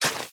Minecraft Version Minecraft Version snapshot Latest Release | Latest Snapshot snapshot / assets / minecraft / sounds / item / shovel / flatten2.ogg Compare With Compare With Latest Release | Latest Snapshot
flatten2.ogg